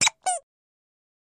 Baby Toy Squeeze Toy, Single Squeeze